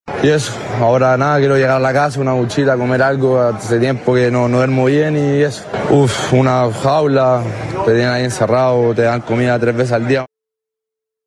A su llegada a Chile, el joven se mostró visiblemente afectado y afirmó que aún está “en estado de shock” tras la experiencia.